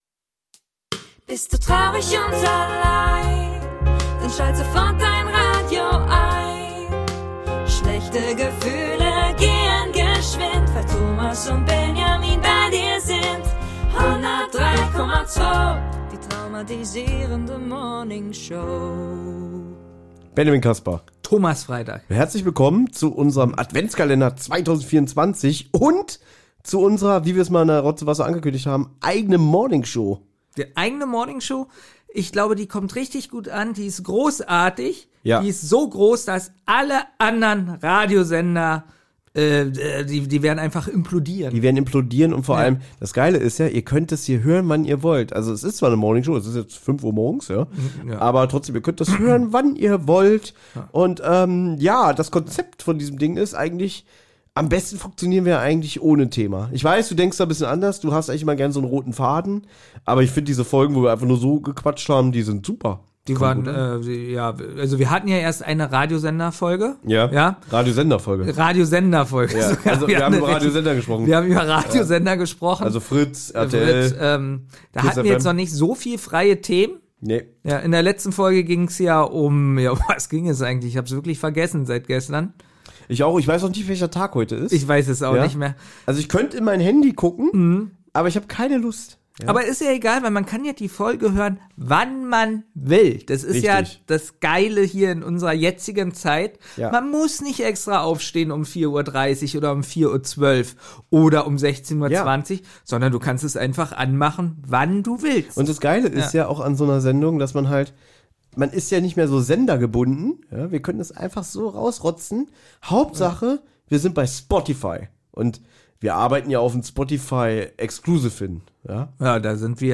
Viel Spaß mit einem lockeren Talk